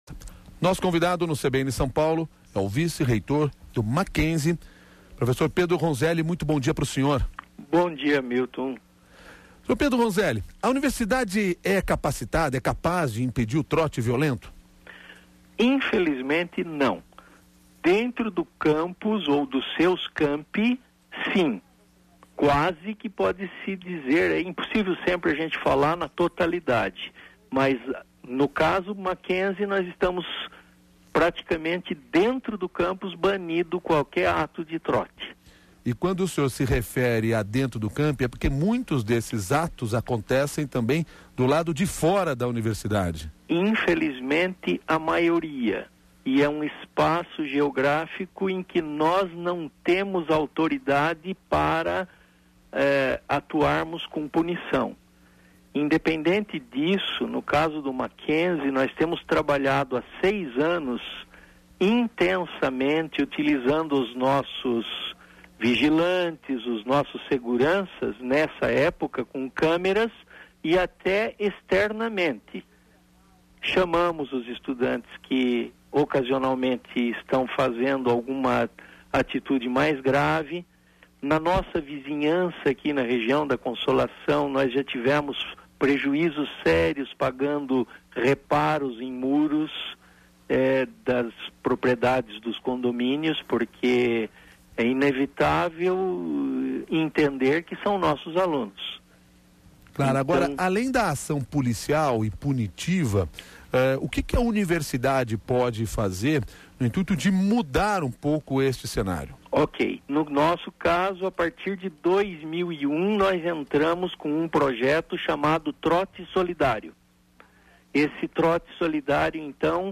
O CBN São Paulo discutiu o tema com dois especialista no assunto: